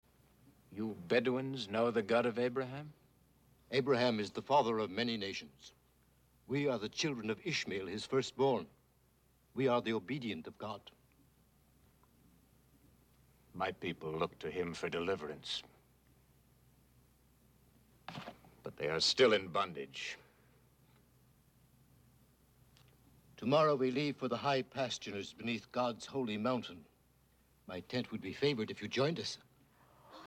Jethro is played by Eduard Franz, an American actor, but somehow (and I might be getting led by the colouring of his robe, here) Jethro’s accent keeps veering towards Ireland.
a-little-bit-irish.mp3